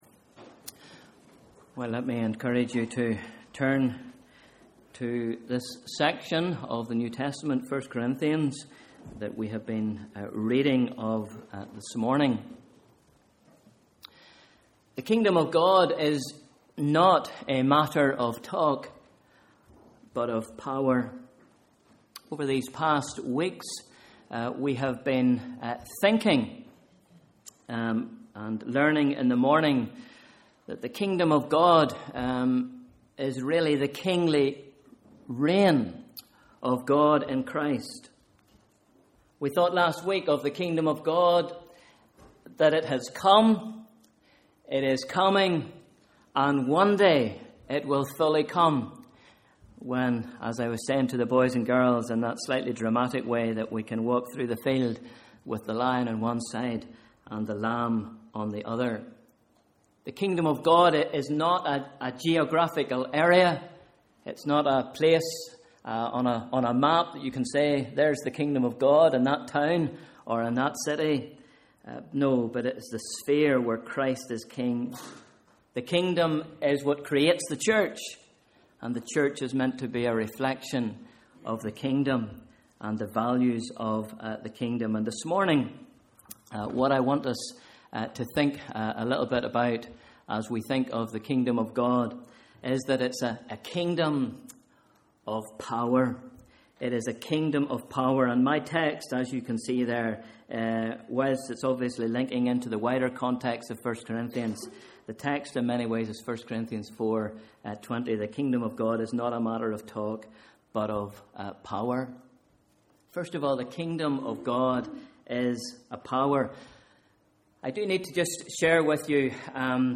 Morning Service: Sunday 21st July 2013